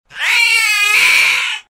catscream
Tags: sfx nekomimi movie effects Wolfshaven Pictures